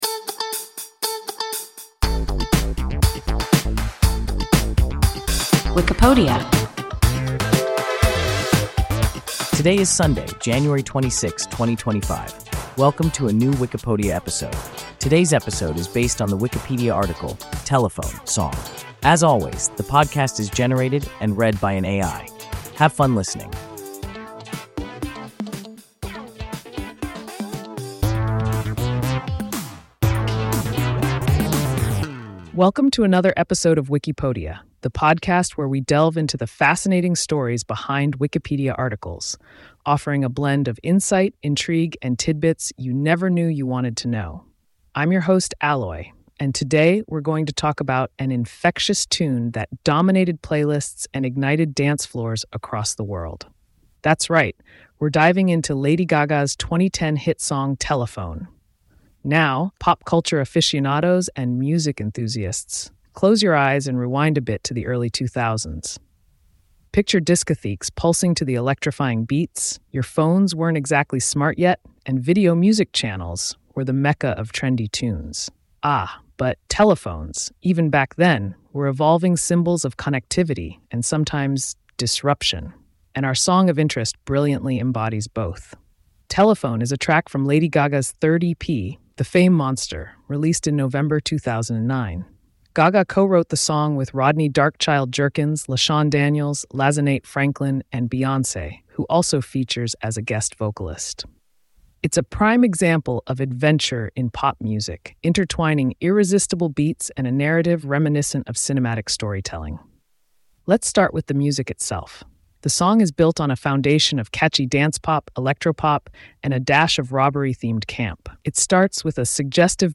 Telephone (song) – WIKIPODIA – ein KI Podcast